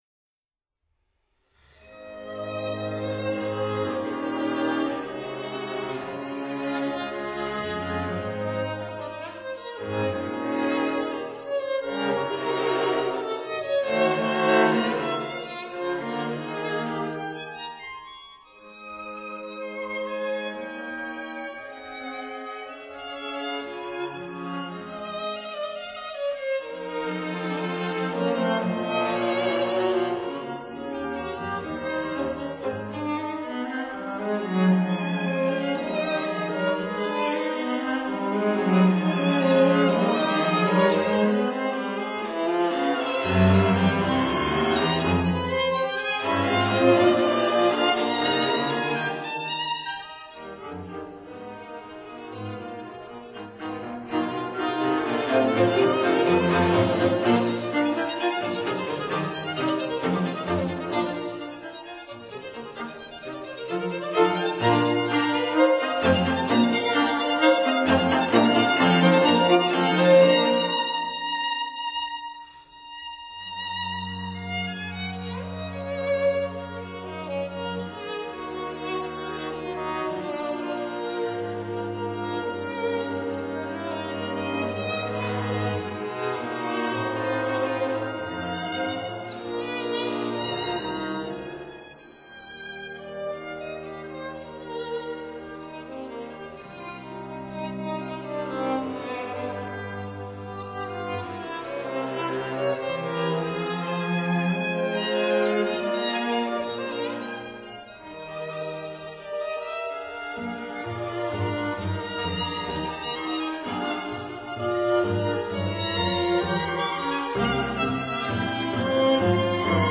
String Quartets by:
(Period Instruments)